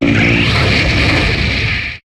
Cri de Pyrax dans Pokémon HOME.